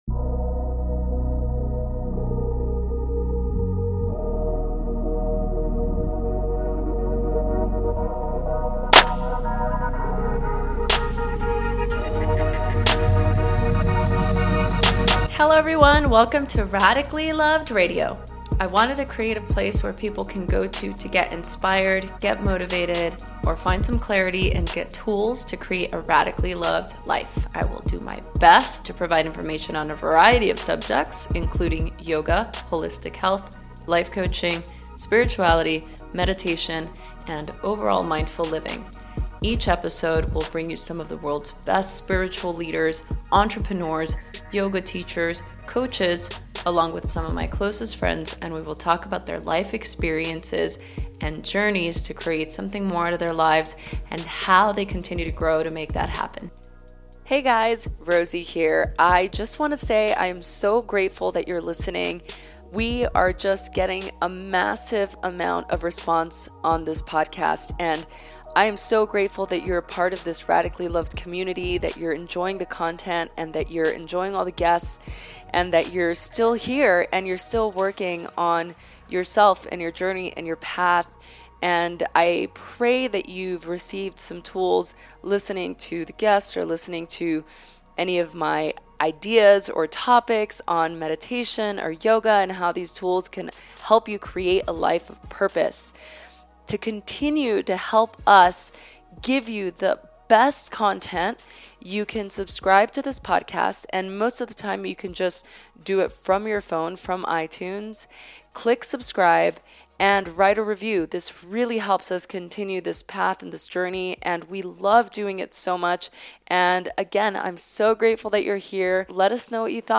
Our amazing guest shares her thoughts on creativity, being enough, and experiencing emotion. She tells us about living with reverence, how we block our souls, and challenging our thoughts.